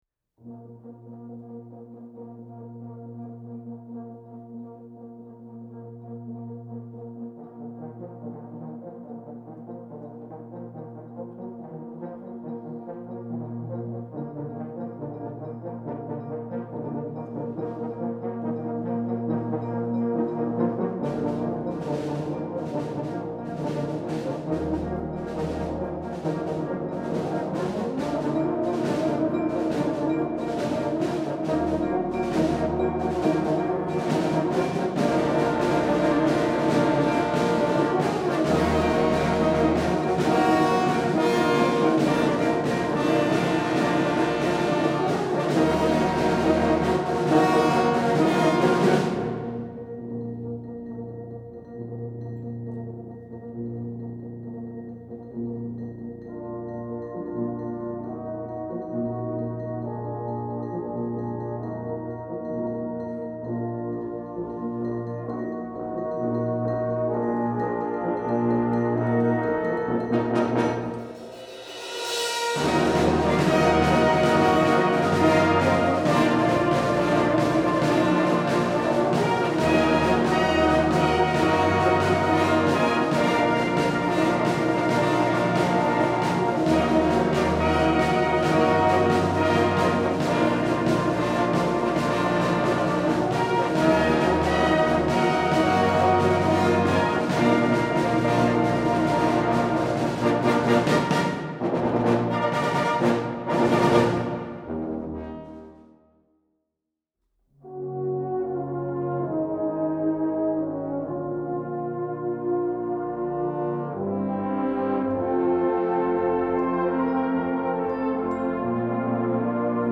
Gattung: Konzertwerk
6:40 Minuten Besetzung: Blasorchester PDF